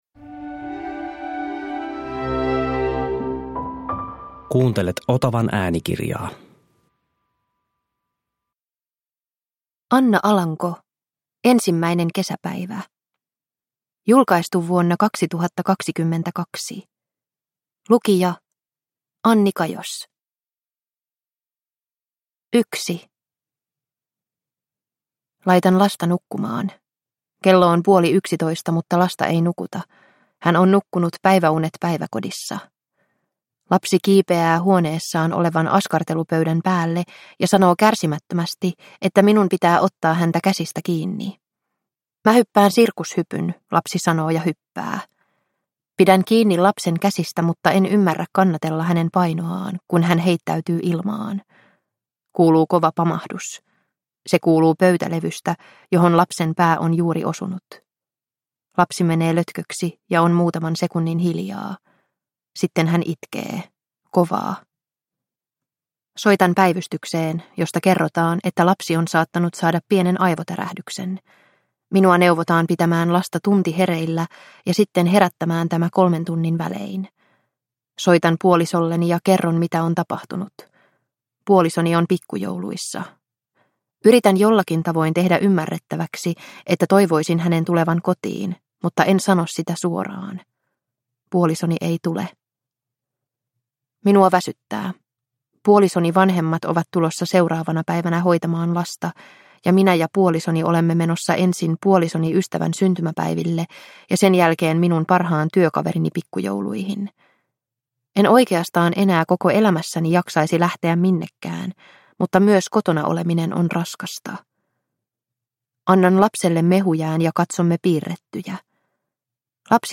Ensimmäinen kesäpäivä – Ljudbok – Laddas ner